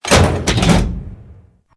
CHQ_FACT_switch_pressed.ogg